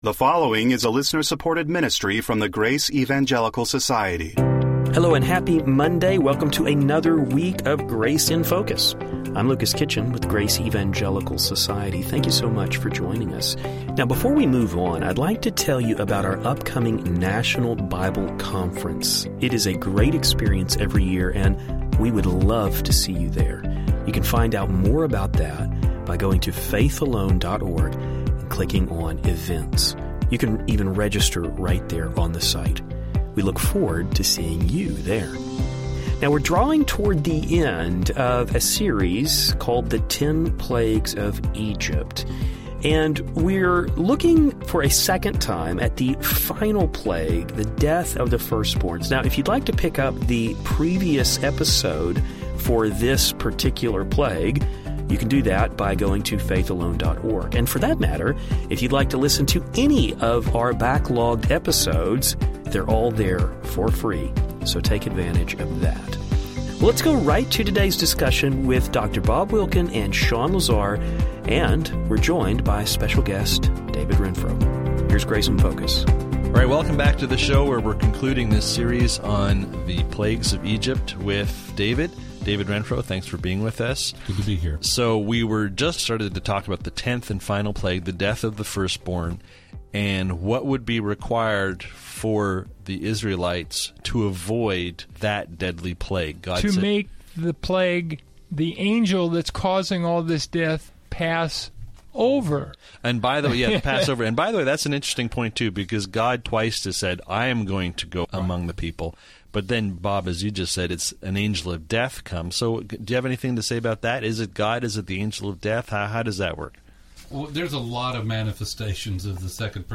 This account is found in Exodus 12 and gives us the origin of the Passover. The guys will discuss the significance of this plague in light of Egyptian paganism.